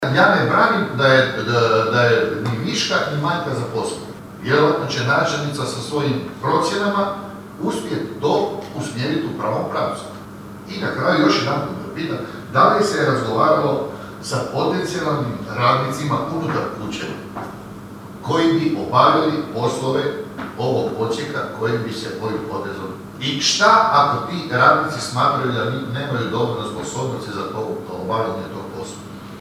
Prijedlog Odluke o II. izmjenama i dopunama Odluke o unutarnjem ustrojstvu i djelokrugu općinske uprave izazvao je žustru raspravu na ovotjednoj sjednici Općinskog vijeća Kršana.
I vijećnik SDP-a Silvano Uravić osvrnuo se na medijske napise o višku zaposlenih, ali i upitao: (